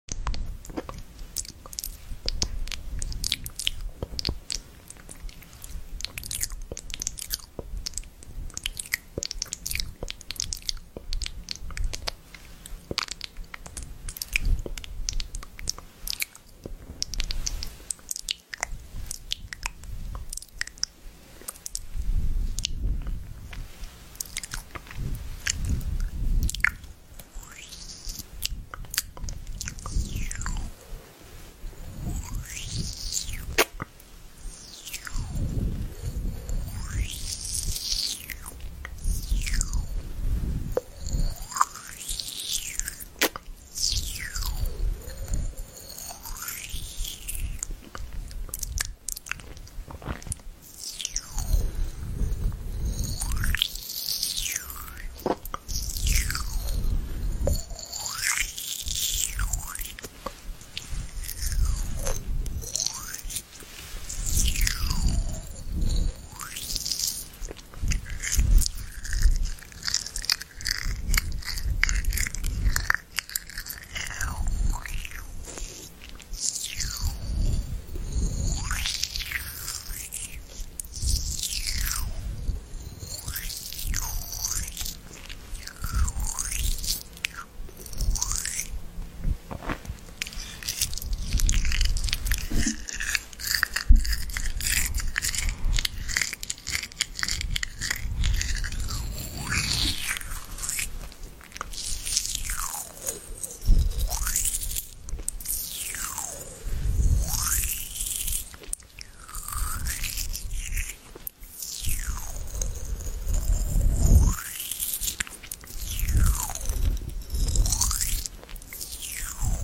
Asmr Eat Strawberry Jam With Sound Effects Free Download